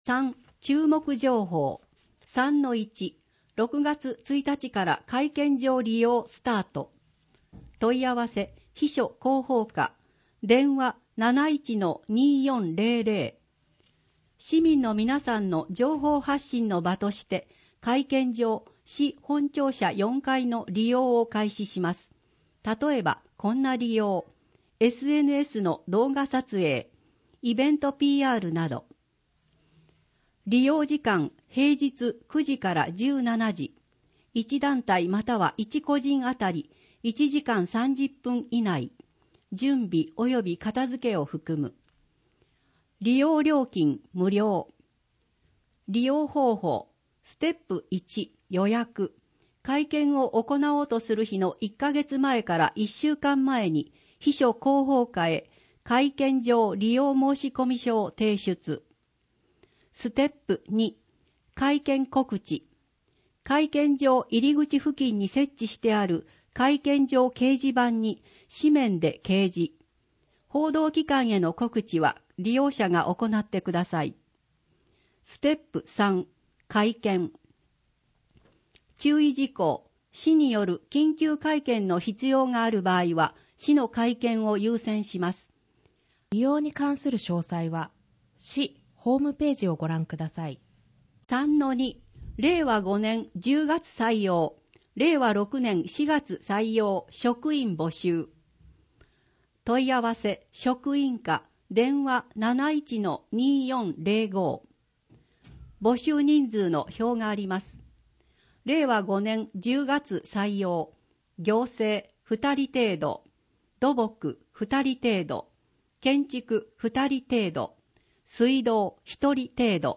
「広報あづみの」を音声でご利用いただけます。